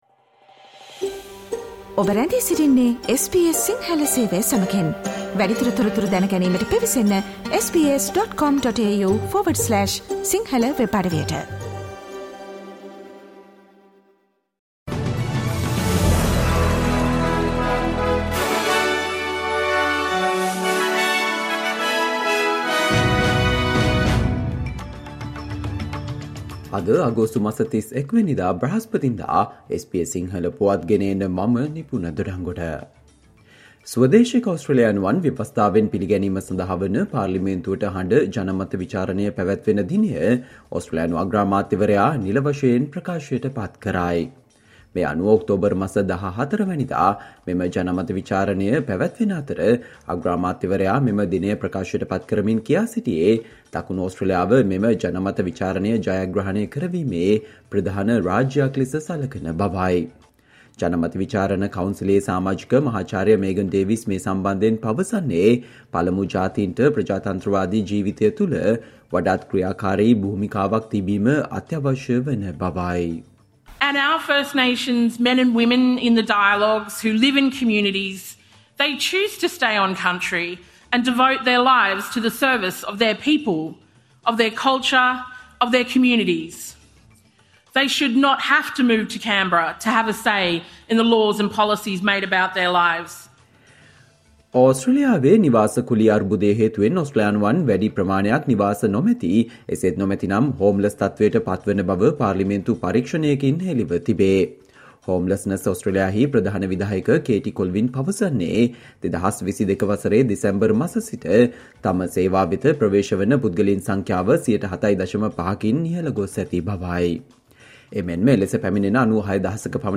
Australia news in Sinhala, foreign and sports news in brief - listen, today - Thursday 31 July 2023 SBS Radio News